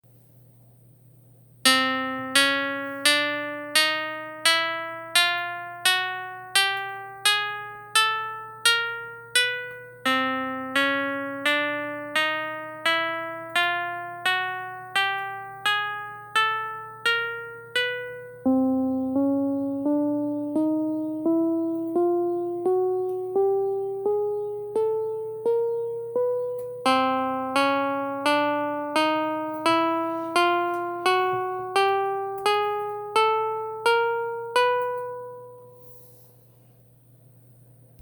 pluck.m4a